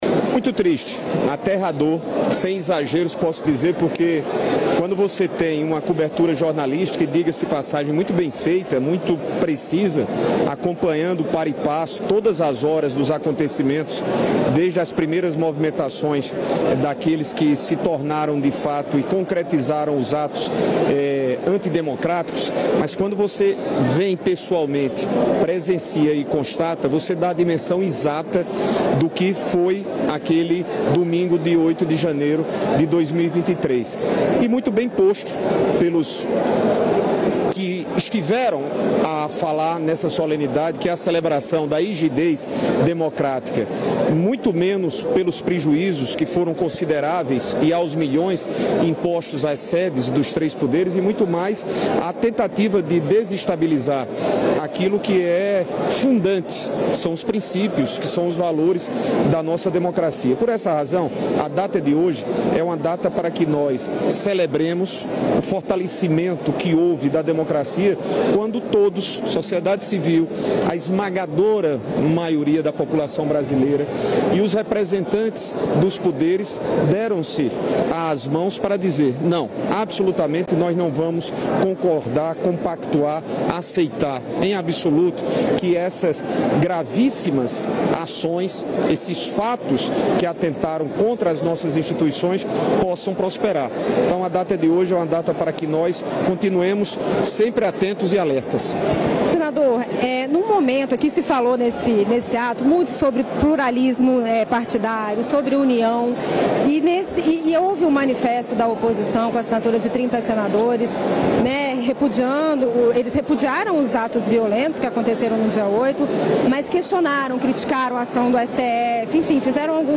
O senador Veneziano Vital do Rêgo (MDB-PB) participou nesta segunda-feira (8) do Ato Democracia Inabalada e lamentou a ausência de parlamentares da oposição.
Reprodução TV Senado